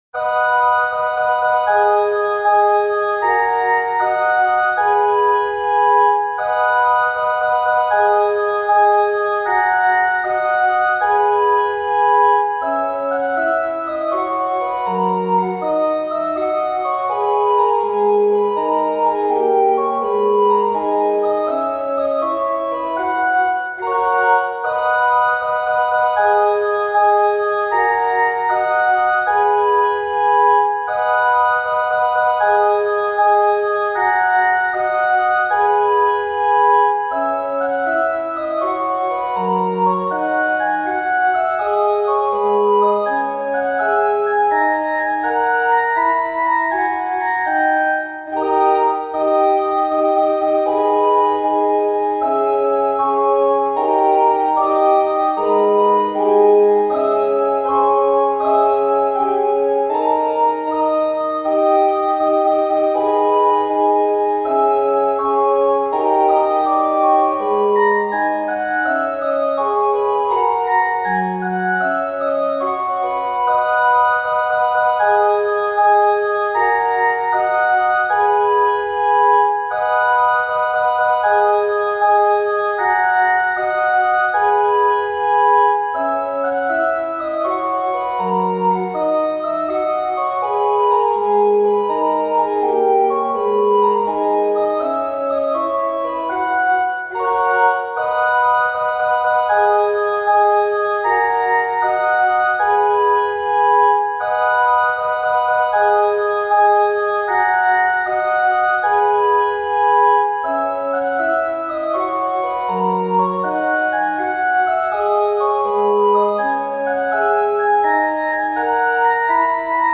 ＜オルガン＞
♪オルガンフルートという丸みのある綺麗な音色のパイプオルガンを使用しています。